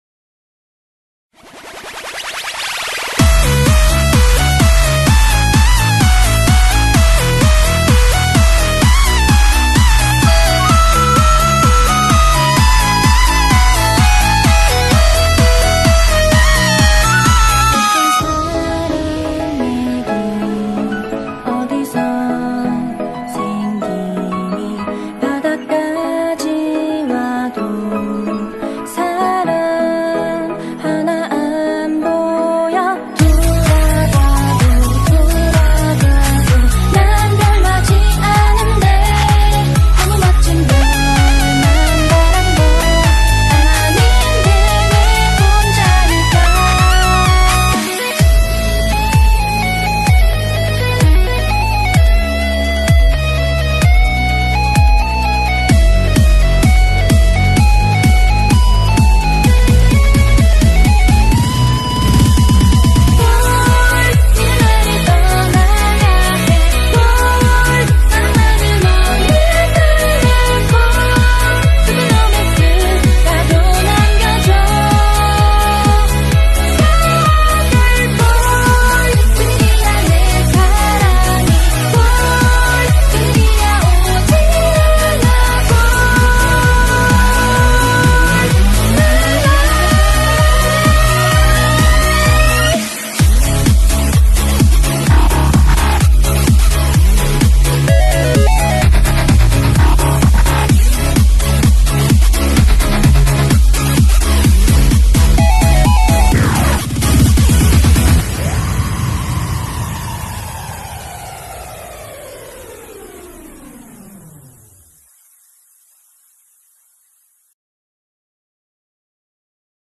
BPM64-128